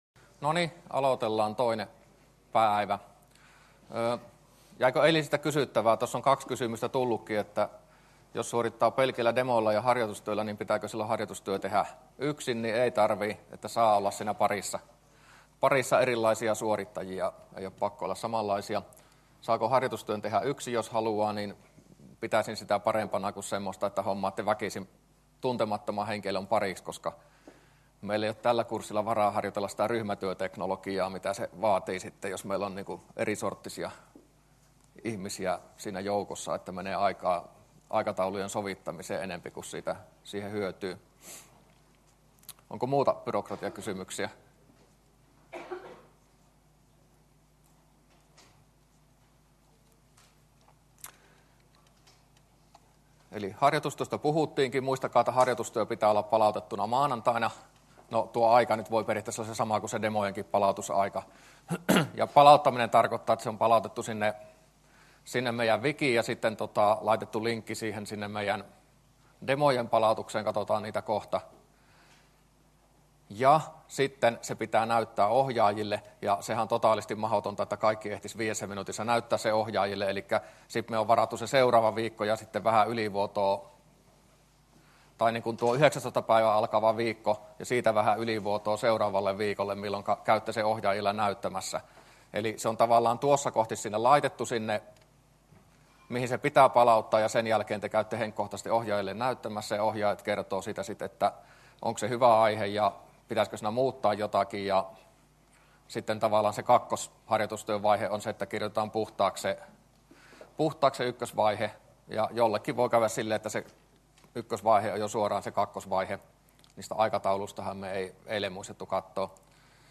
luento02a